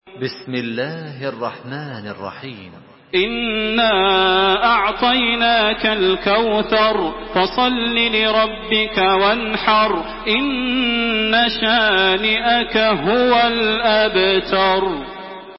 Makkah Taraweeh 1427
Murattal